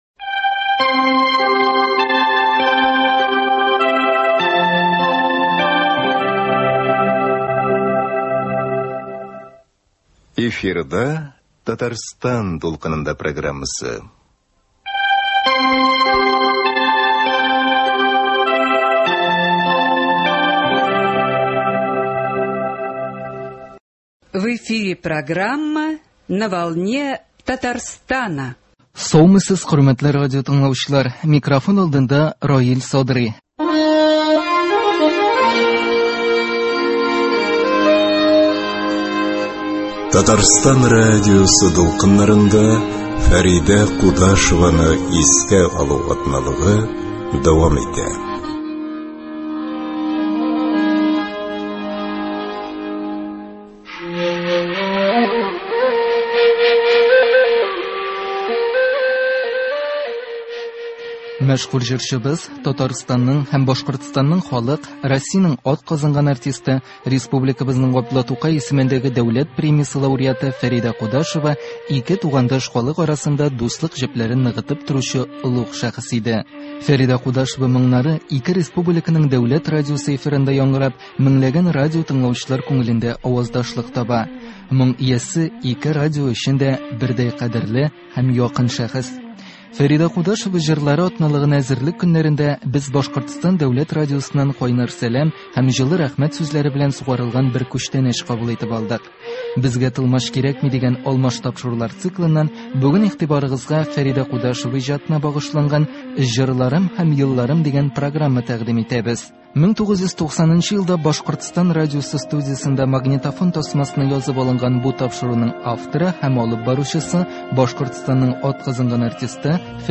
1990 елда Башкортстан радиосы студиясендә магнитофон тасмасына язып алынган